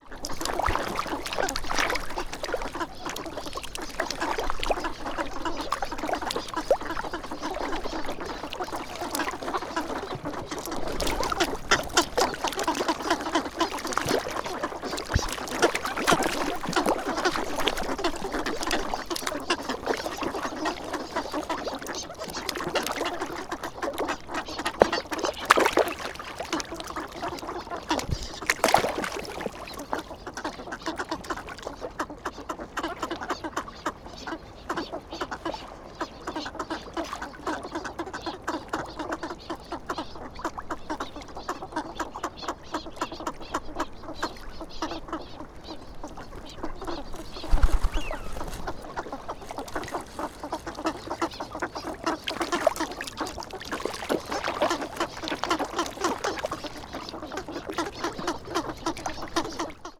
Fukushima Soundscape: Abukuma River